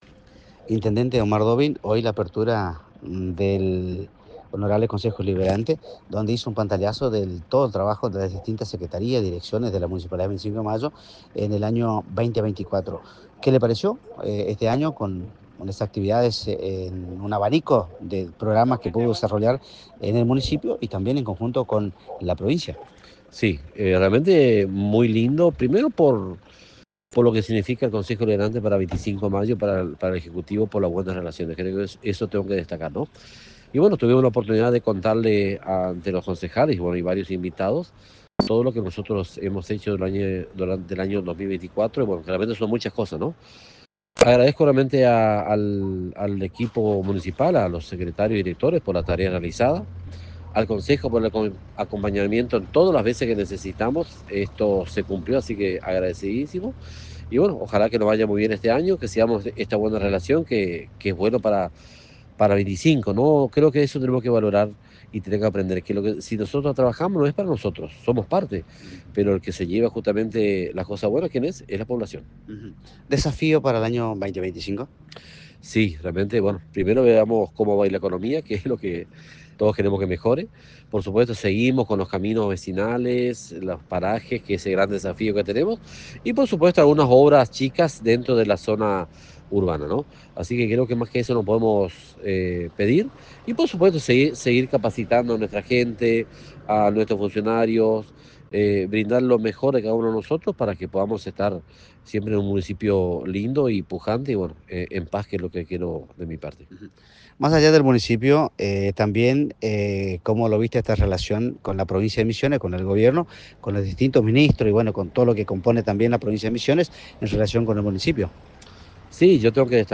Audio del Intendente Omar Wdowin